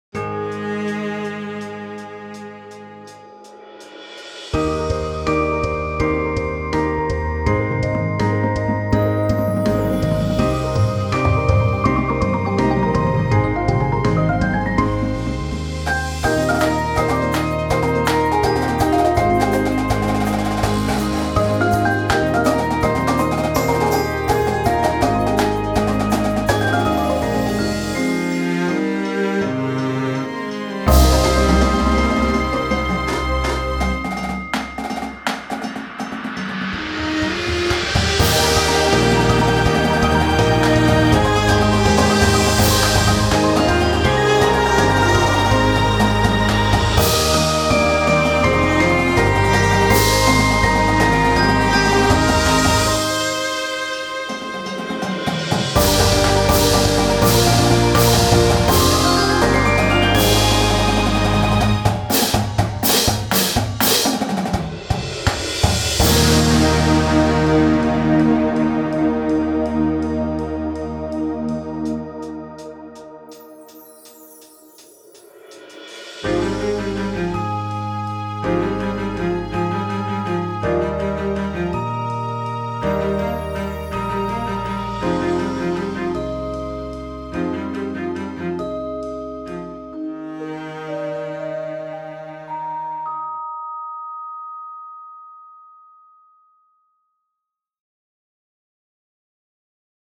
Class: Scholastic A Movement
Instrumentation: Full Ensemble Percussion and Cello